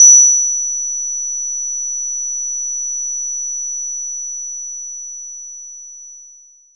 Player_EarRinging.wav